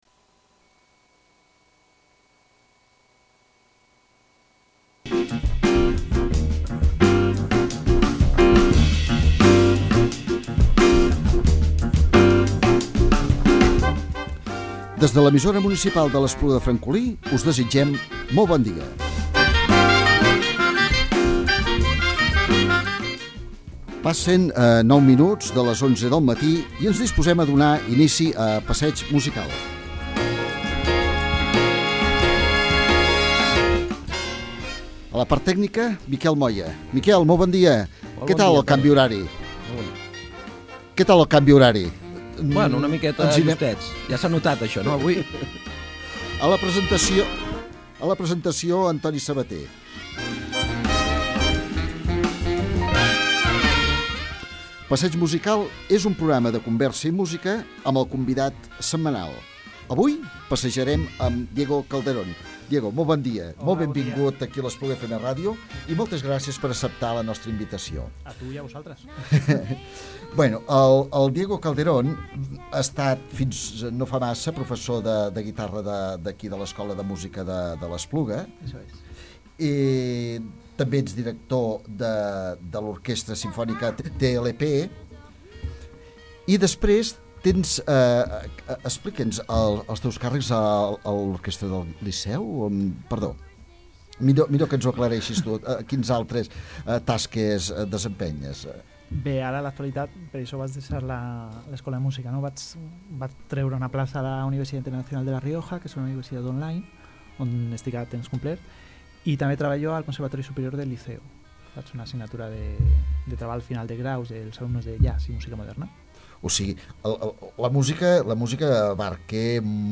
La tria que ha fet, han estat unes músiques delicades i intimistes, ha predominat composicions de cantautor.